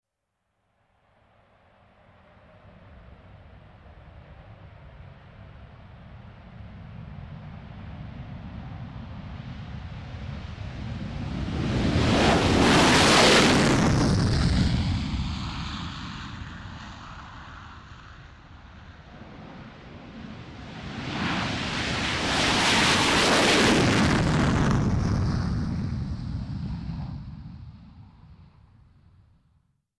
Two F-18 hornet jet fighters take off directly overhead